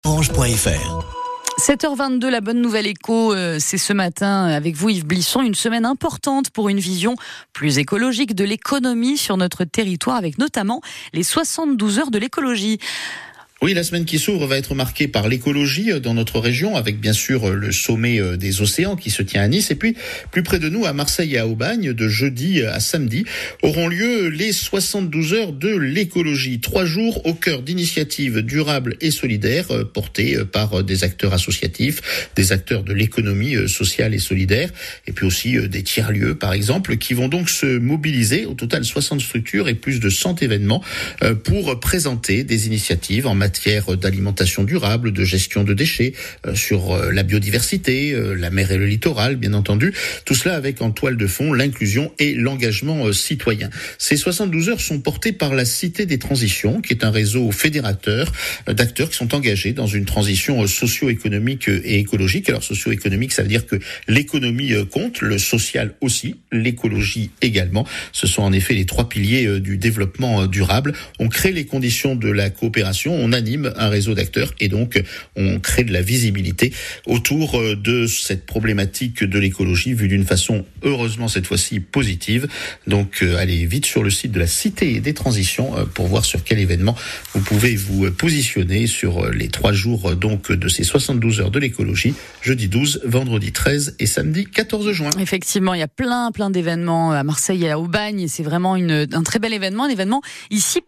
La chronique d\